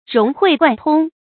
注音：ㄖㄨㄙˊ ㄏㄨㄟˋ ㄍㄨㄢˋ ㄊㄨㄙ
融會貫通的讀法